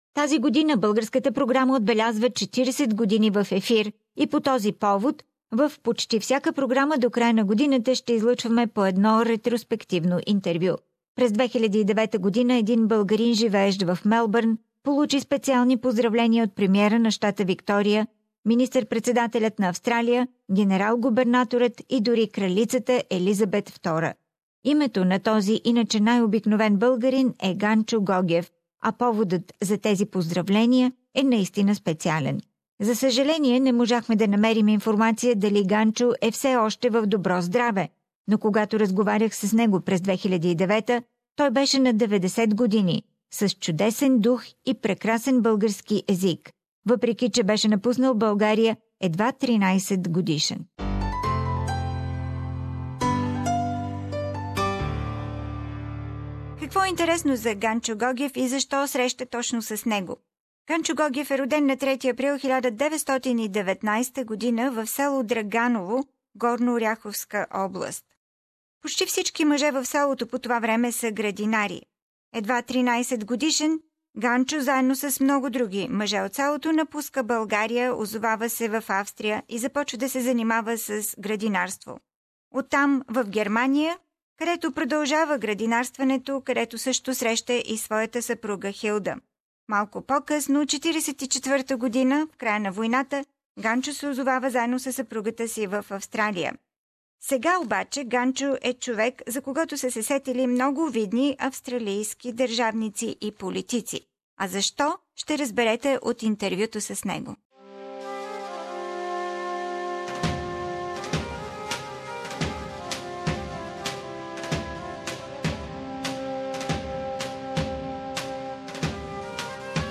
This year, the Bulgarian program on SBS Radio celebrates its 40th birthday and on this occasion we broadcast a flashback interviews.